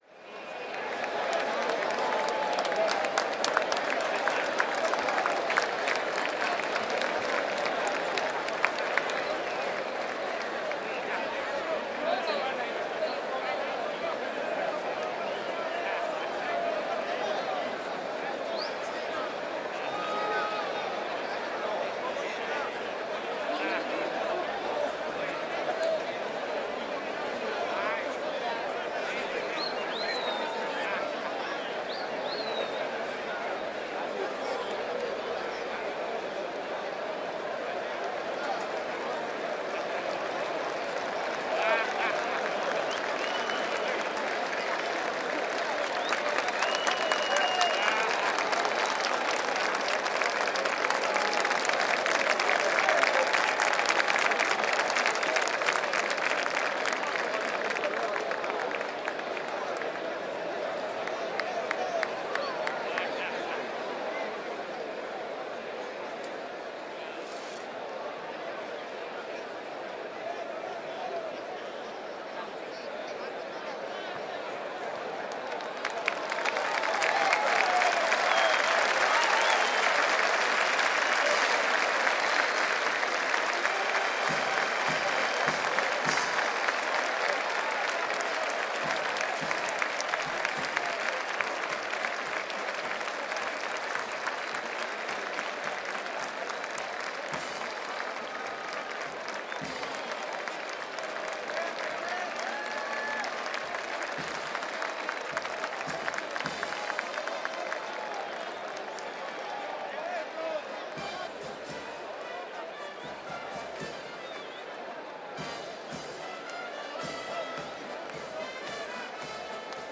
PLAZA DE TOROS ACHO ANTES DE CORRIDA APLAUSOS INICIO BANDA DE MÚSICOS – Los sonidos del Perú
PLAZA-DE-TOROS-ACHO-ANTES-DE-CORRIDA-APLAUSOS-INICIO-BANDA-DE-MÚSICOS.mp3